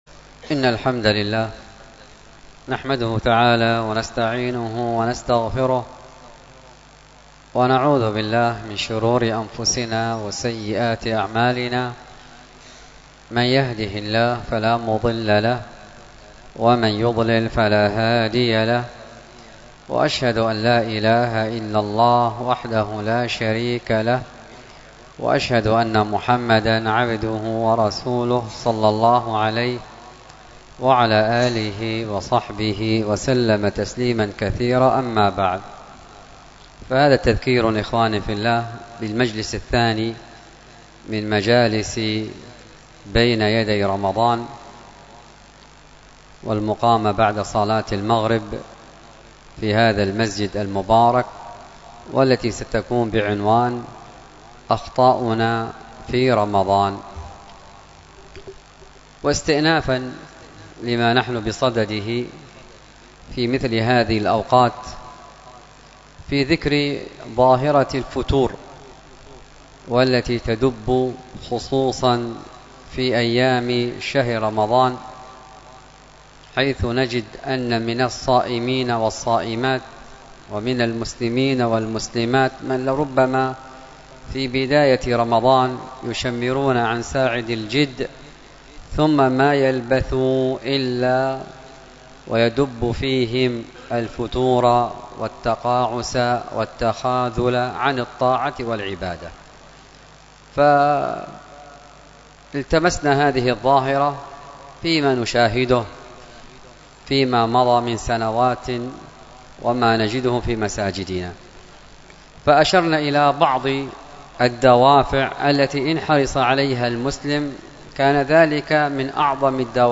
الدرس في شرح أصول في التفسير للعثيمين 17، ألقاها